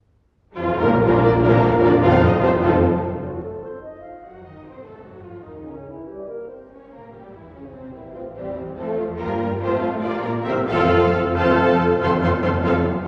↑古い録音のため聴きづらいかもしれません！（以下同様）
Allegro vivace – Un poco meno Allegro
～快活に速く　-　（トリオは）少し遅く～
メヌエットとトリオが交互に現れる楽章です。